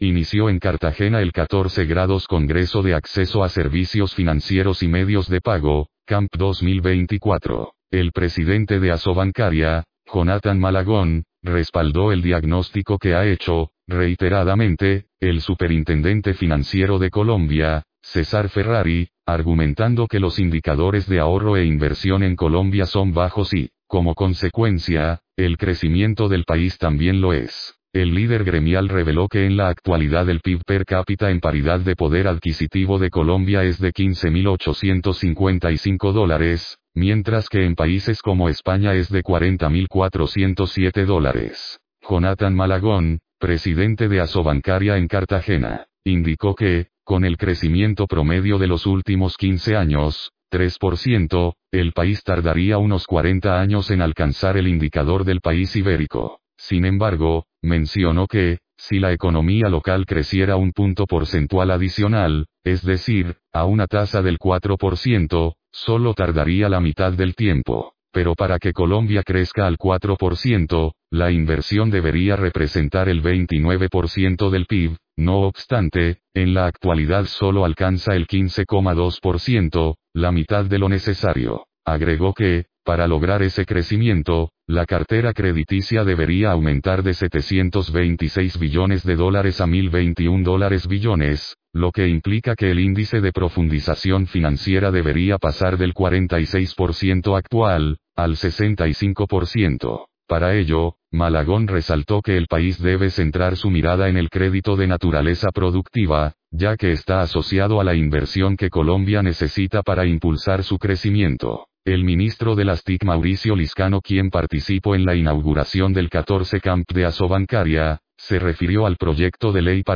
Jonathan Malagón, presidente de Asobancaria en el 14° Congreso de Acceso a Servicios Financieros y Medios de Pago – CAMP 2024.